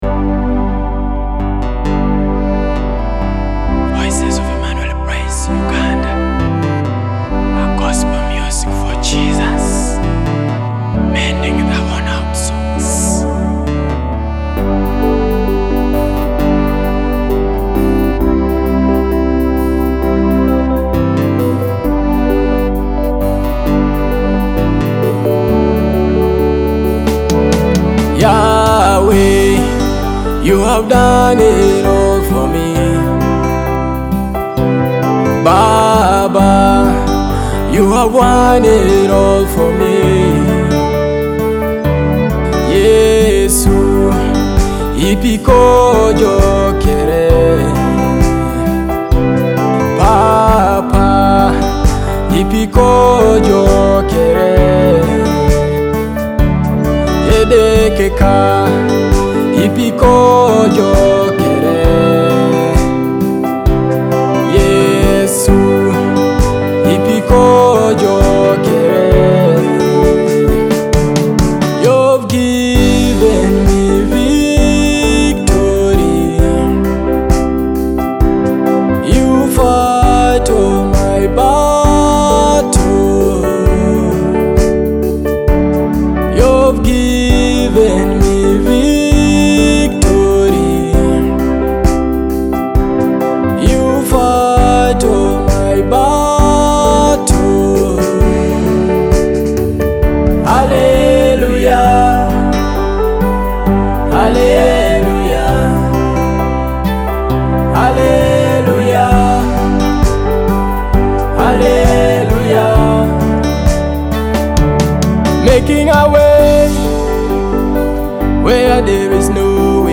a powerful gospel worship song that declares God’s victory